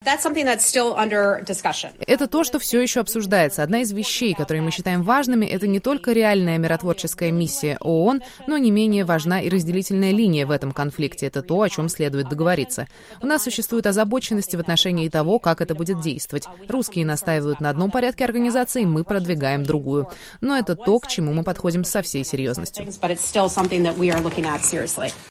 Пресс-секретарь Госдепартамента США Хизер Нойерт, выступая во вторник (19 декабря) на брифинге в Вашингтоне, призвала Россию положить конец боевым действиям на востоке Украины и согласиться на размещение миссии миротворцев ООН.